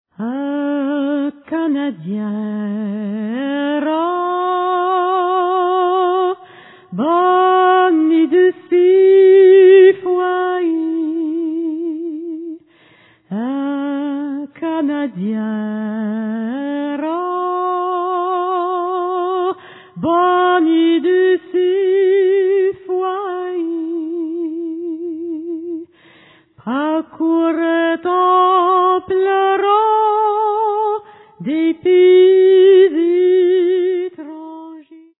French and Breton folk songs